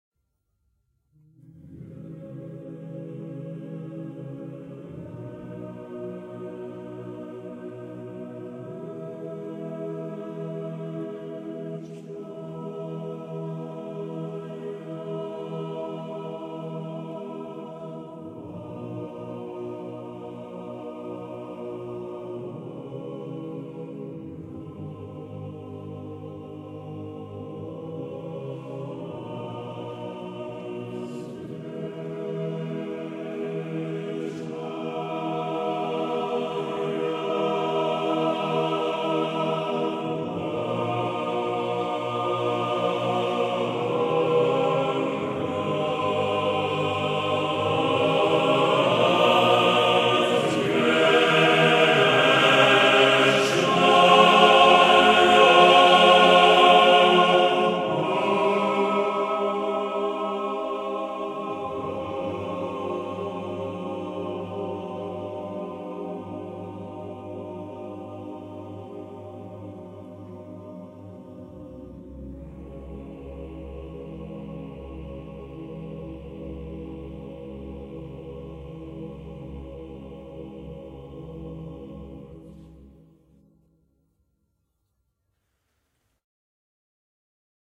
Canto del
Vechnaya-Pamyat-Eternal-Memory-traditional-Russian.mp3